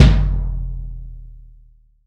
Index of /90_sSampleCDs/AKAI S6000 CD-ROM - Volume 3/Kick/GONG_BASS
GONG BASS2-S.WAV